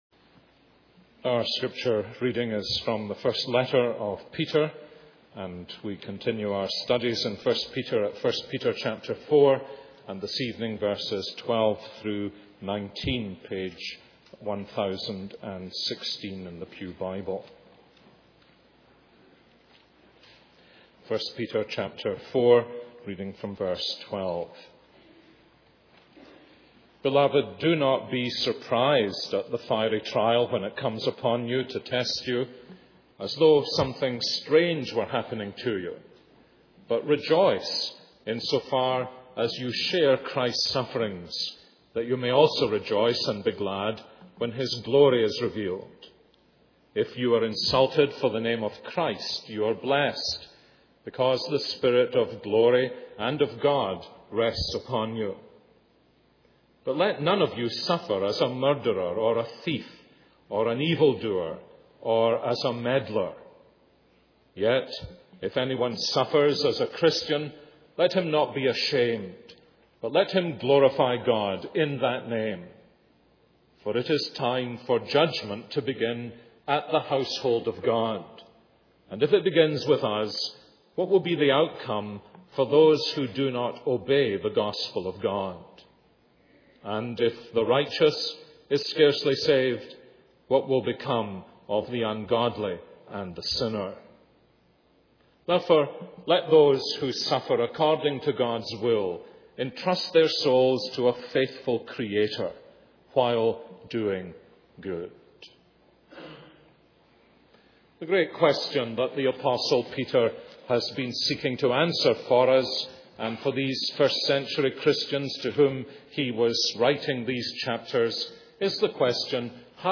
This is a sermon on 1 Peter 4:12-19.